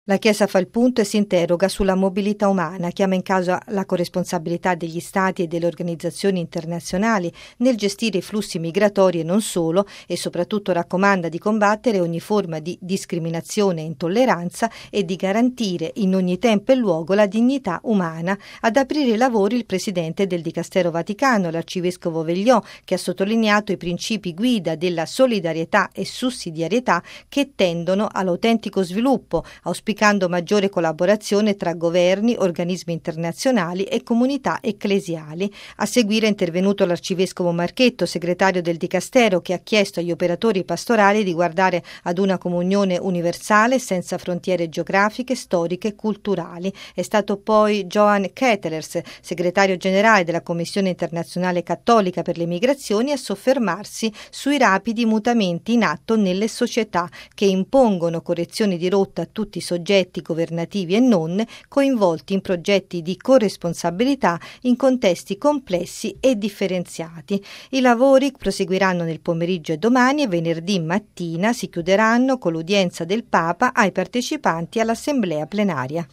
◊   Aperta stamane in Vaticano la XIX Assemblea plenaria del Pontificio Consiglio della pastorale per i migranti e gli itineranti, dedicata a studiare il fenomeno della mobilità umana, mutevole e in crescita, per offrire risposte anzitutto alla persona. Il servizio